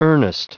Prononciation du mot earnest en anglais (fichier audio)
Prononciation du mot : earnest